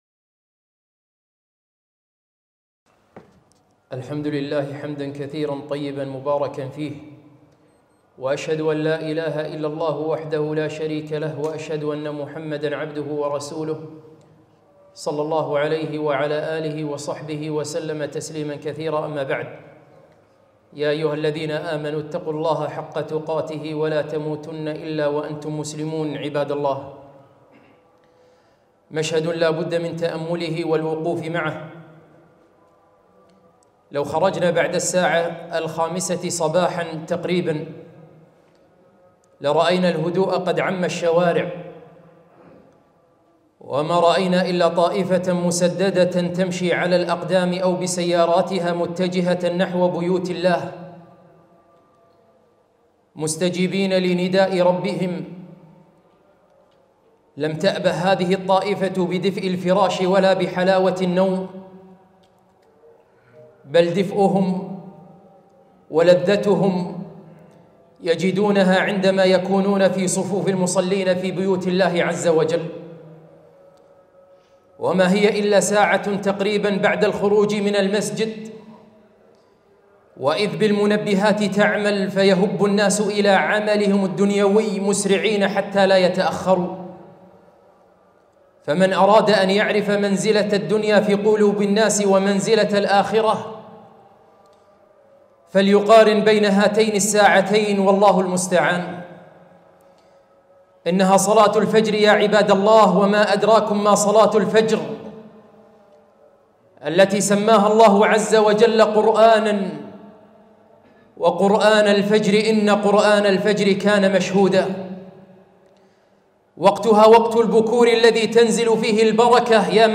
خطبة - أهل الفجر